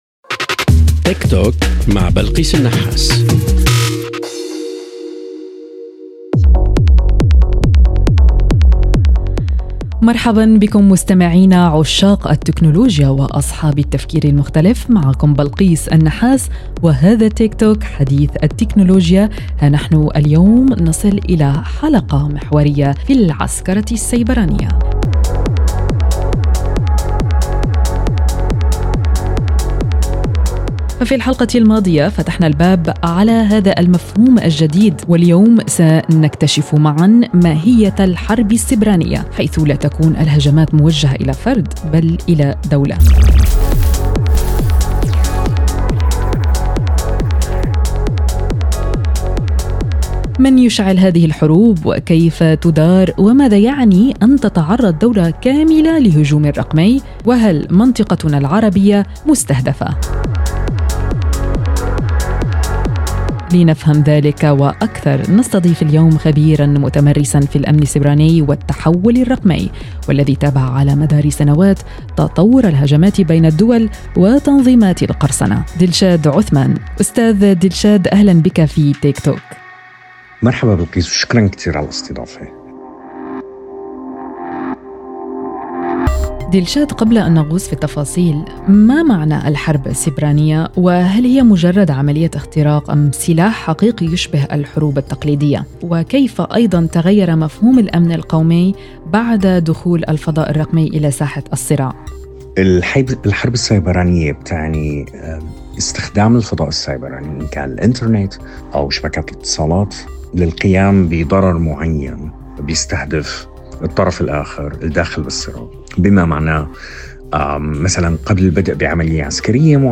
يجيبنا الخبير الأمني